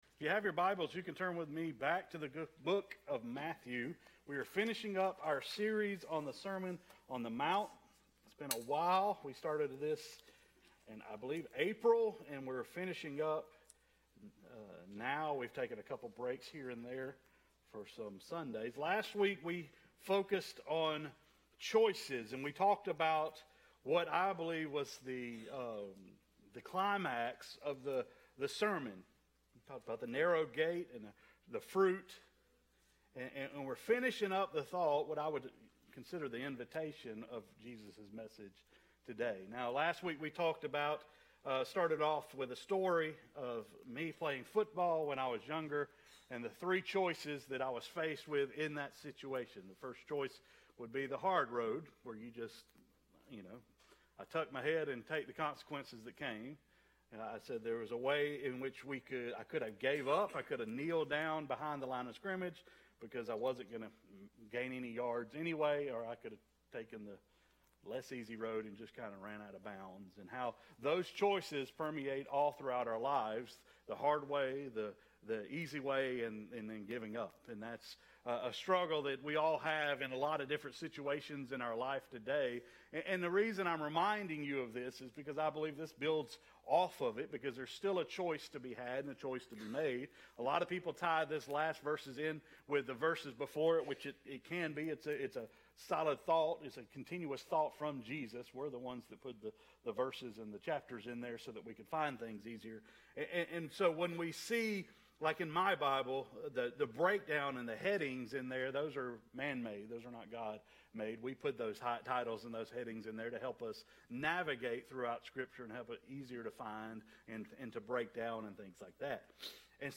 Sermons | Piney Grove Baptist Church